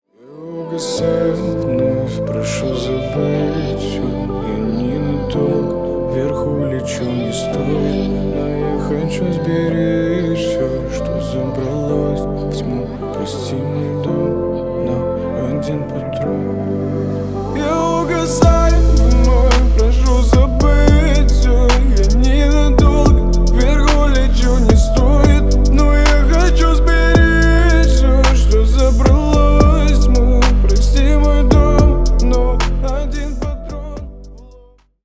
Поп Музыка
грустные # тихие